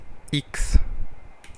Ääntäminen
France (Paris): IPA: /iks/